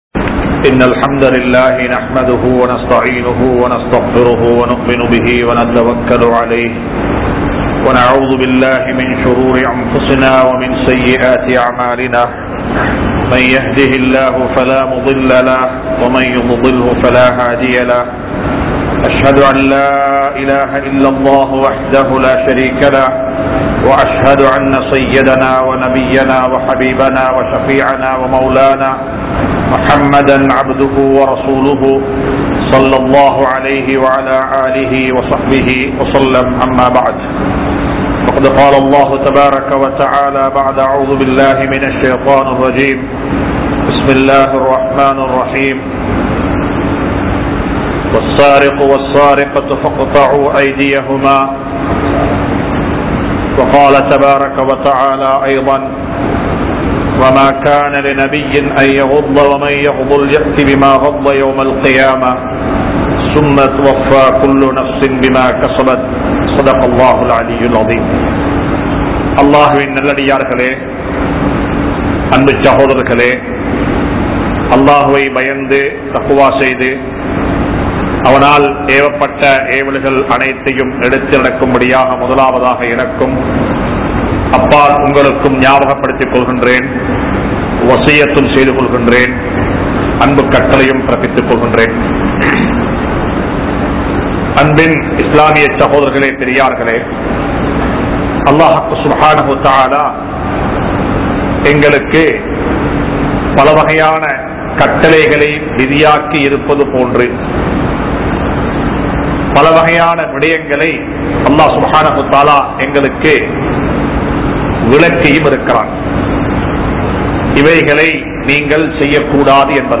Thiruttin Vilaivuhal (திருட்டின் விளைவுகள்) | Audio Bayans | All Ceylon Muslim Youth Community | Addalaichenai